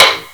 taiko-soft-hitwhistle.wav